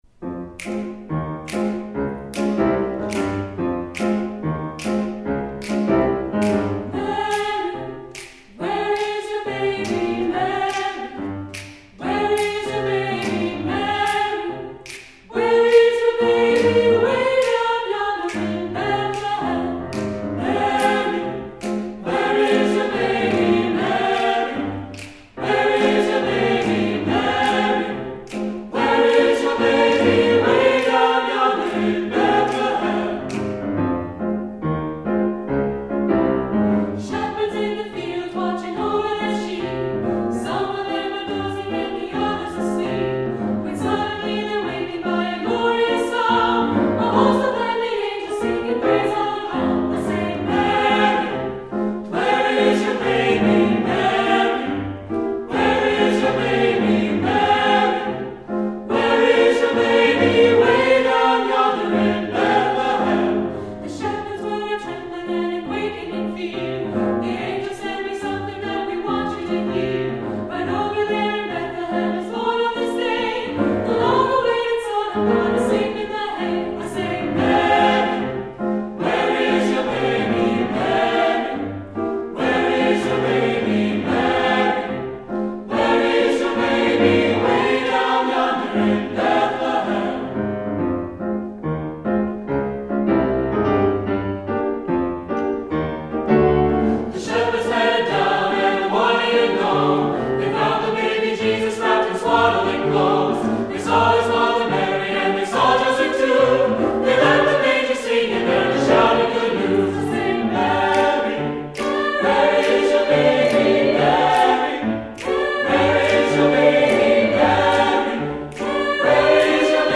Voicing: SAT